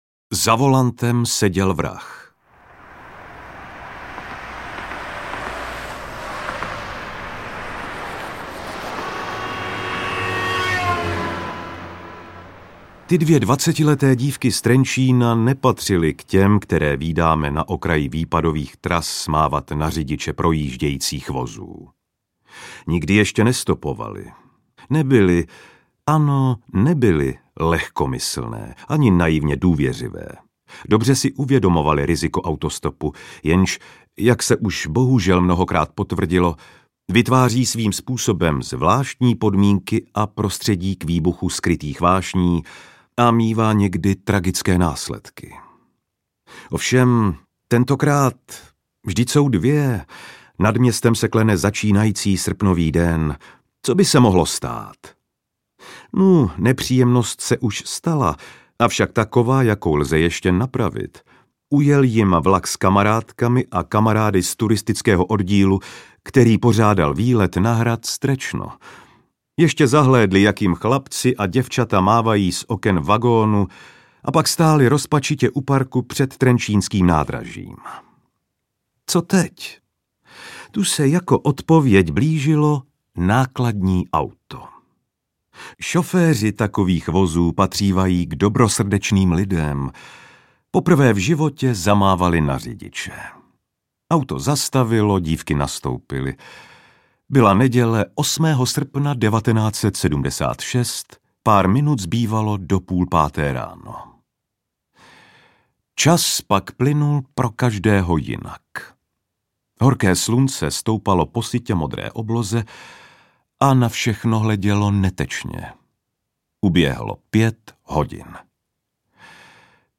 Interpret:  Petr Stach
AudioKniha ke stažení, 10 x mp3, délka 3 hod. 1 min., velikost 165,0 MB, česky